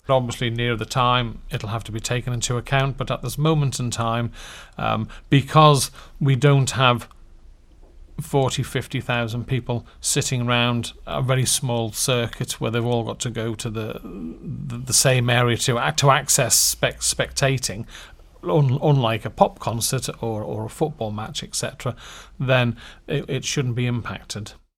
Mr Quayle says that will be considered, when appropriate: